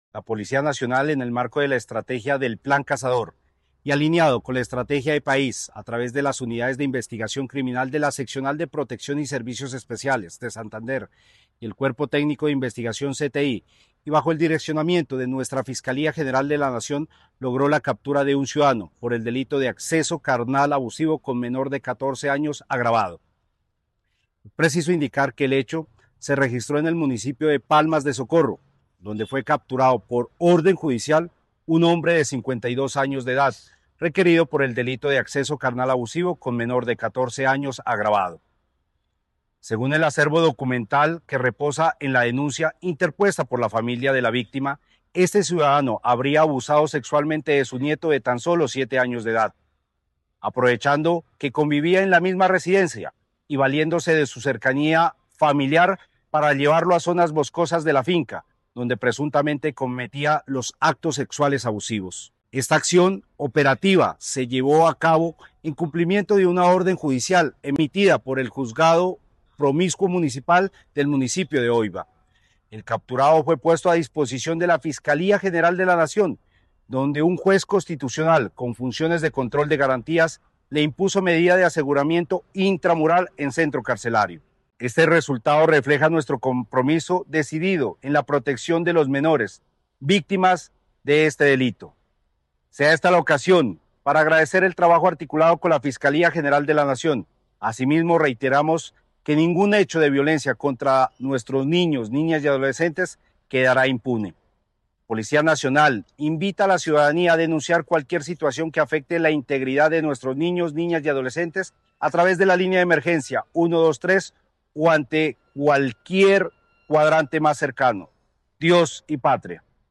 coronel Carlos Efrén Fuelagán, Comandante de la Policía de Santander